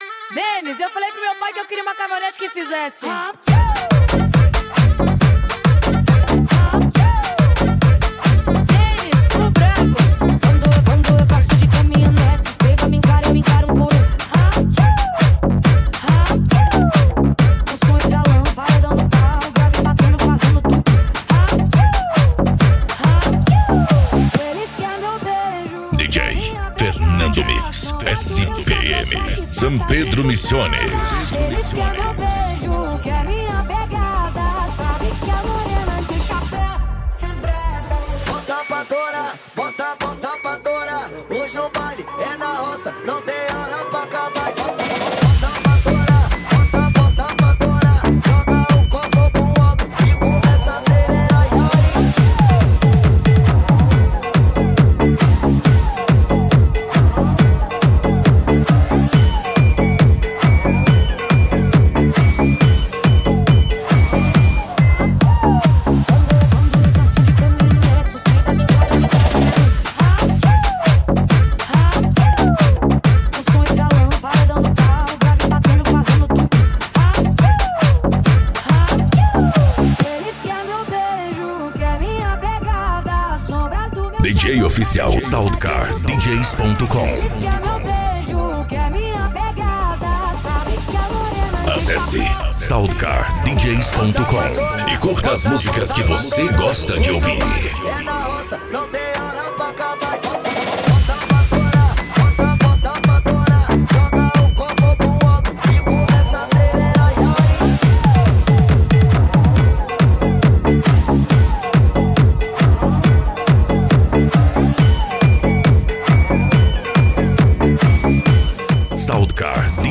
Com Grave Bass Aumentado ( Para escuchar en Alto Volumen)